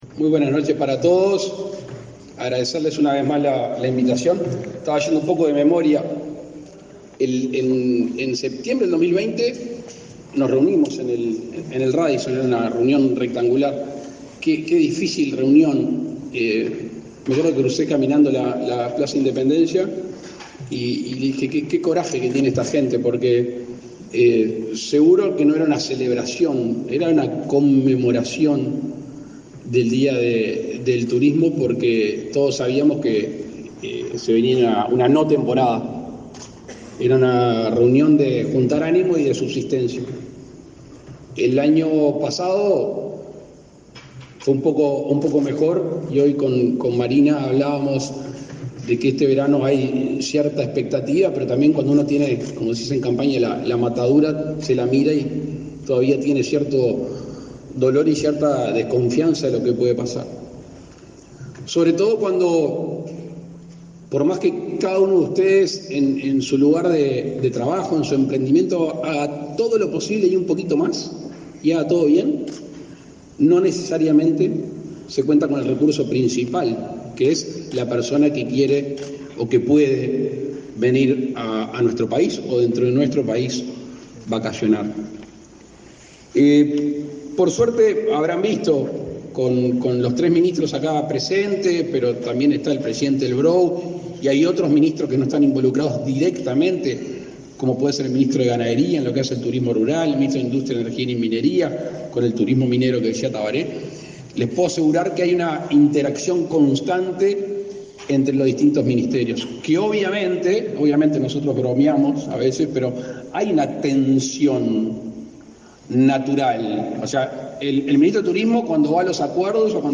Conferencia de prensa por el Día Mundial del Turismo
Con la presencia del presidente de la República, Luis Lacalle Pou, se celebró, este 27 de setiembre, el Día Mundial del Turismo.